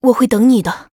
文件 文件历史 文件用途 全域文件用途 Yoshua_fw_03.ogg （Ogg Vorbis声音文件，长度0.9秒，130 kbps，文件大小：14 KB） 文件说明 源地址:游戏语音解包 文件历史 点击某个日期/时间查看对应时刻的文件。 日期/时间 缩略图 大小 用户 备注 当前 2019年1月20日 (日) 04:27 0.9秒 （14 KB） 地下城与勇士  （ 留言 | 贡献 ） 分类:寒冰之休亚 分类:地下城与勇士 源地址:游戏语音解包 您不可以覆盖此文件。